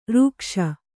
♪ rūkṣa